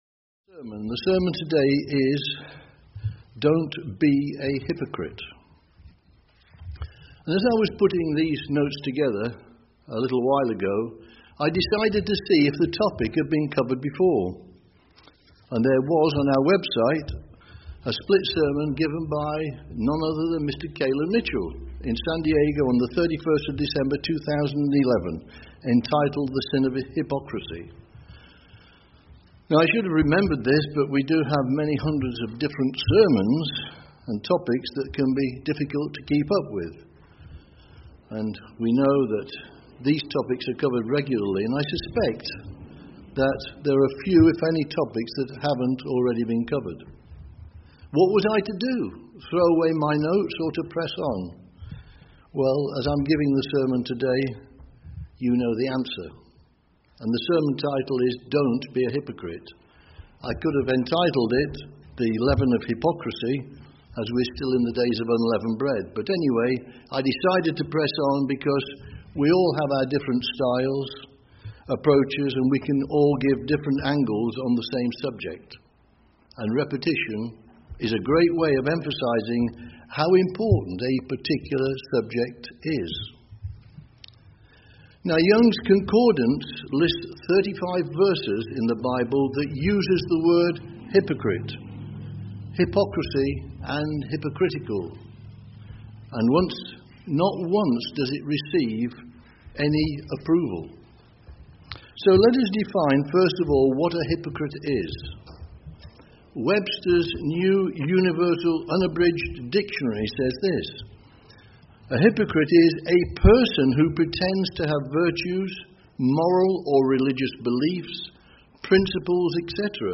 Sermons – Page 96 – Church of the Eternal God